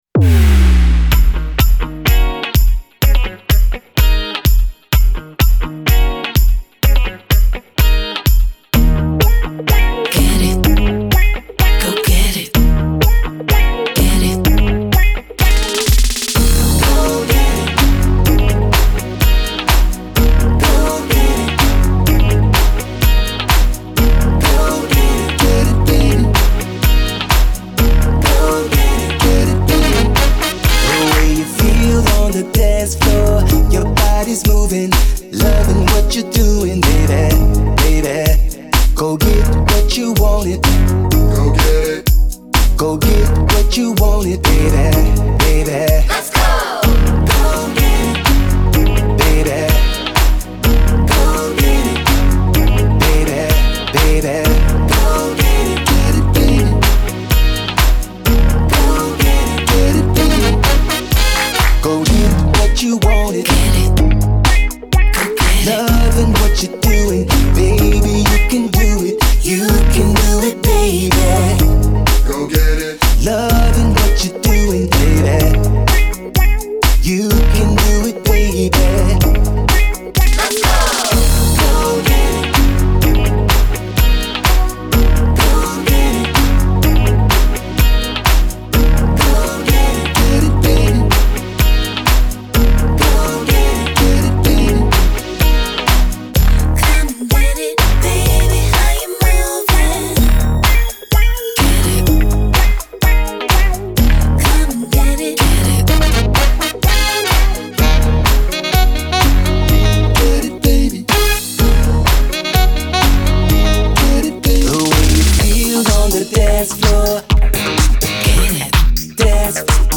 Genre : Funk